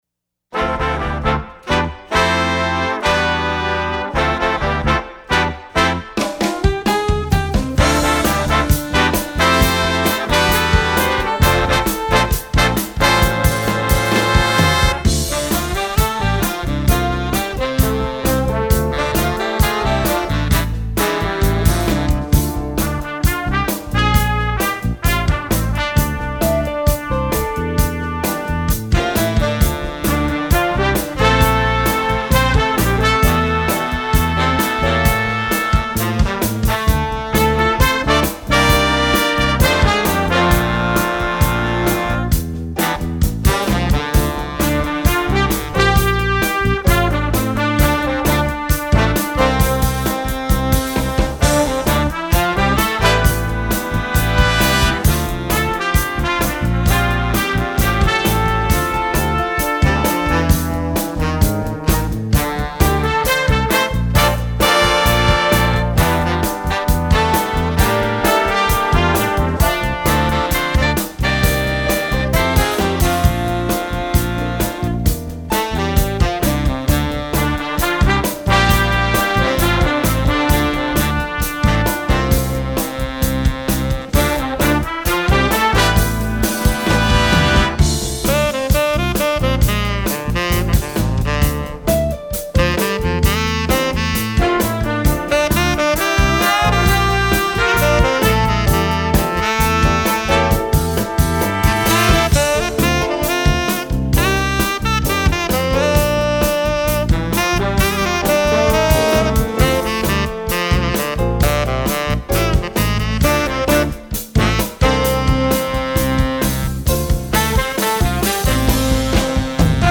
jazz, rock